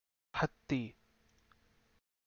Captions English Kannada pronunciation of "hatti"